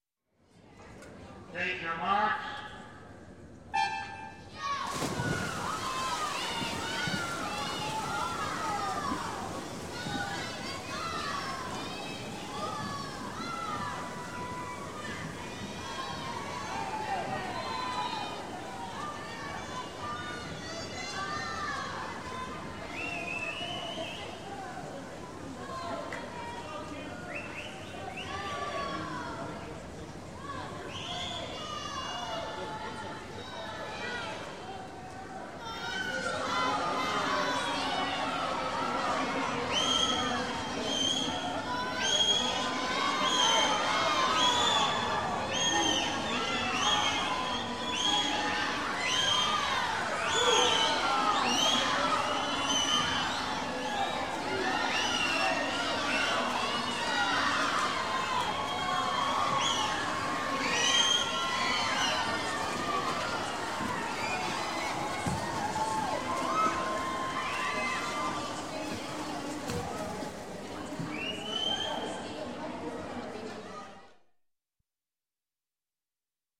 Звуки плавания
Звук заплыва на соревнованиях